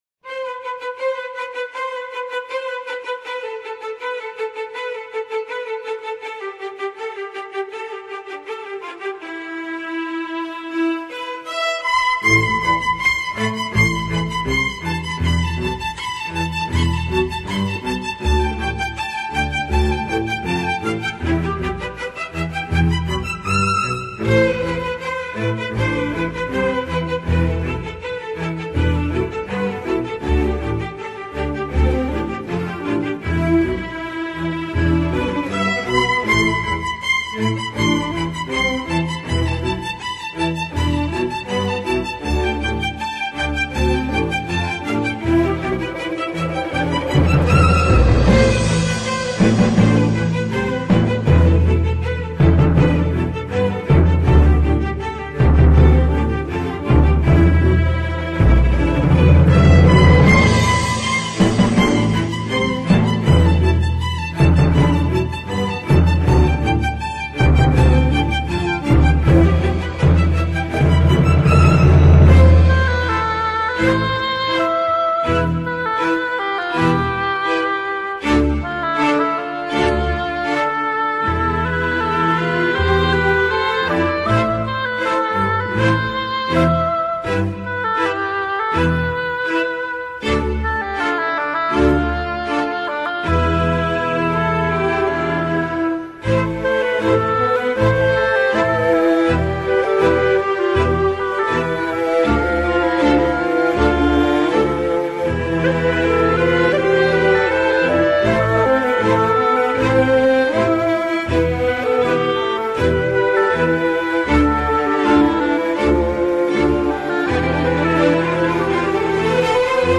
-istiklal mar��-fon m�zik